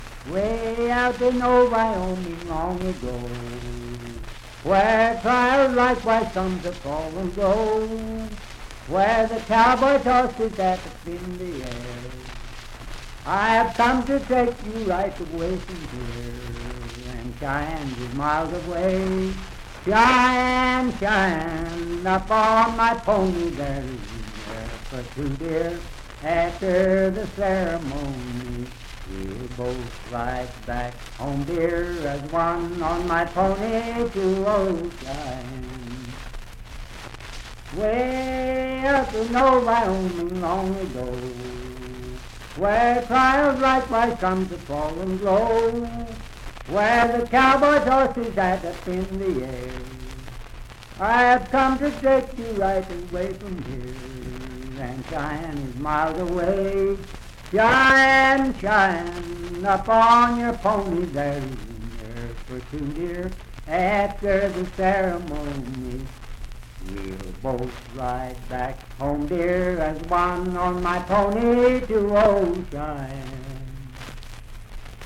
Voice (sung)
Parkersburg (W. Va.), Wood County (W. Va.)